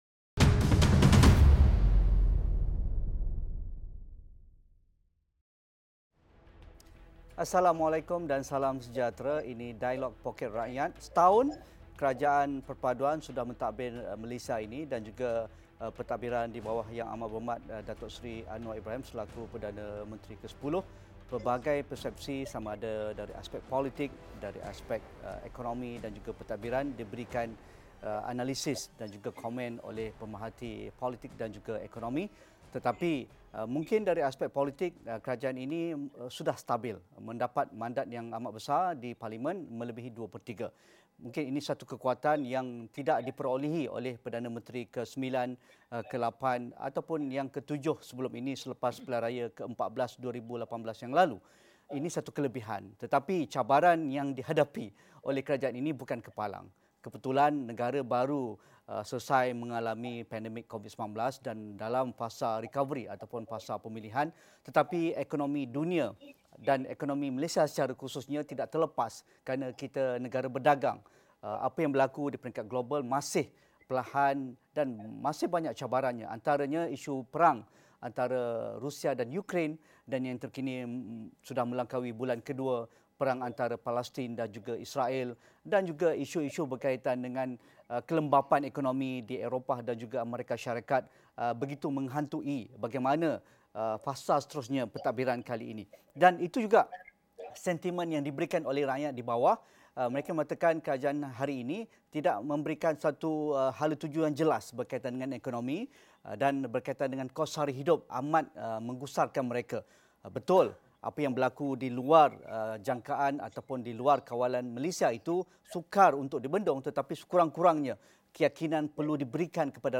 Adakah Malaysia berada di landasan yang tepat dari aspek ekonomi dan meningkatkan taraf hidup rakyat selepas setahun pentabiran kerajaan perpaduan? Diskusi dan analisis dalam Dialog Poket Rakyat 8:30 malam ini.